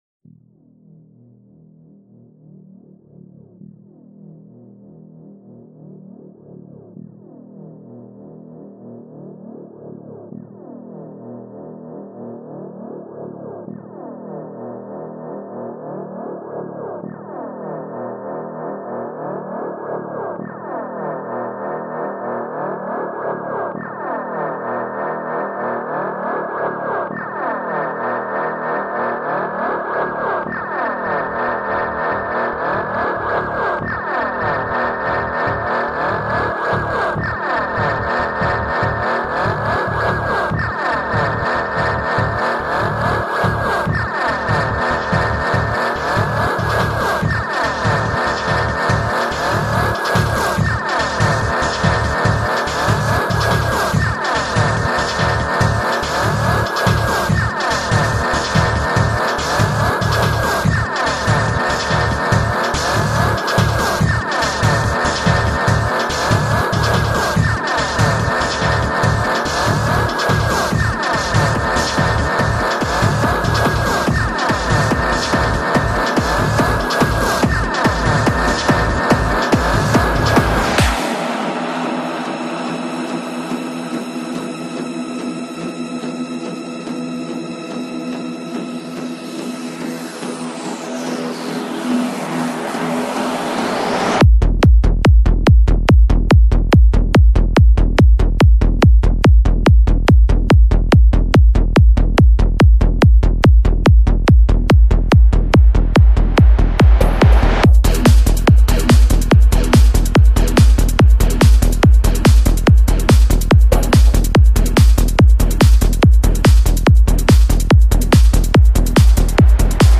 música electrónica